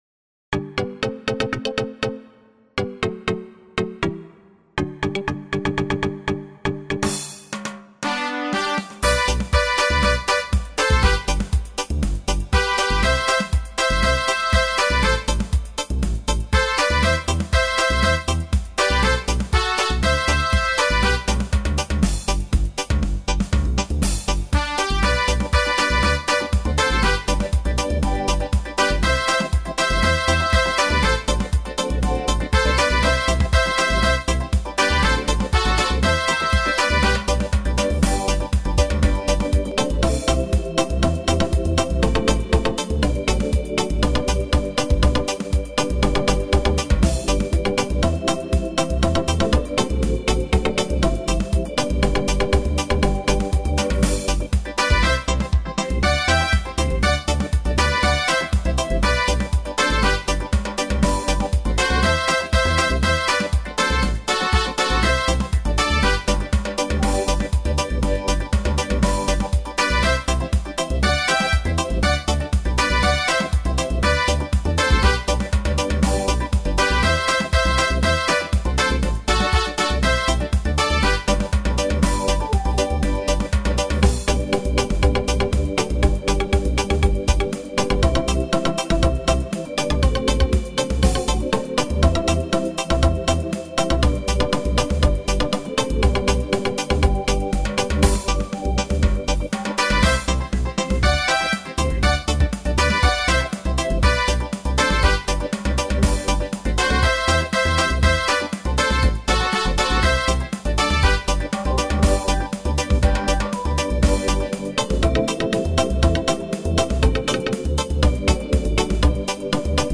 Traditional Carnival Soca from Trinidad.